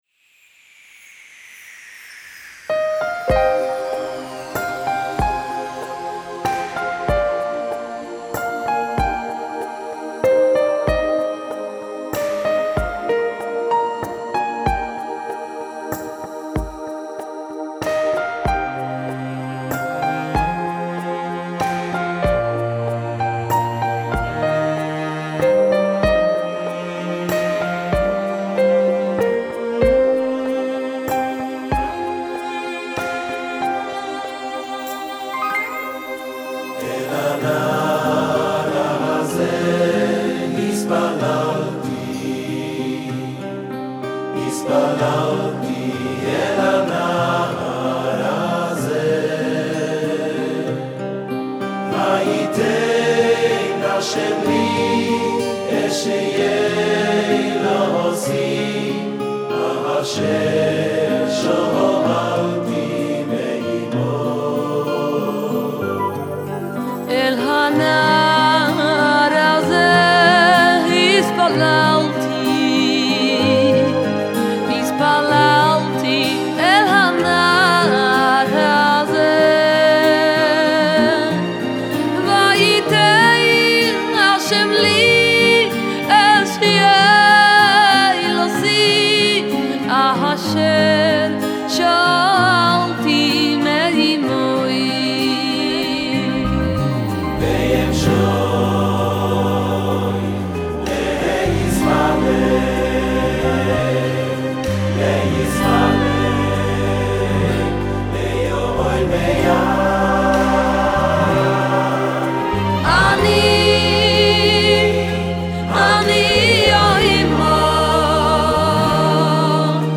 לראשונה בביצוע לייב אגדי!